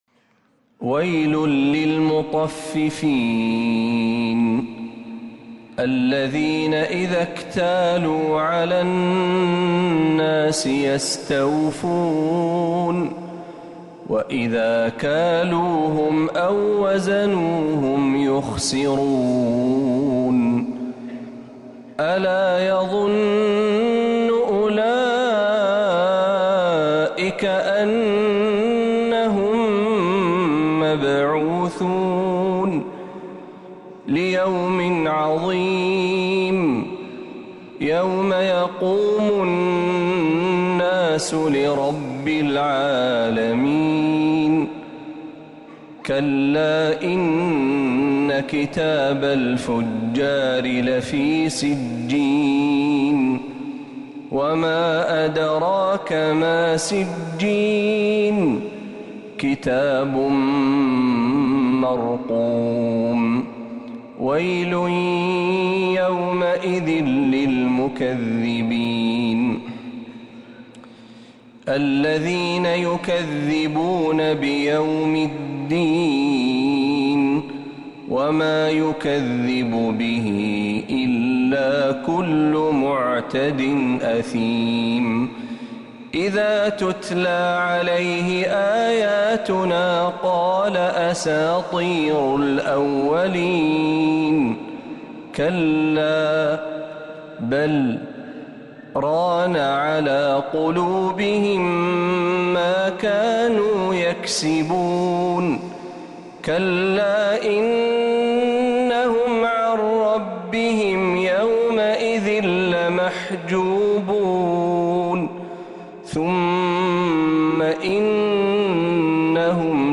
سورة المطففين كاملة من الحرم النبوي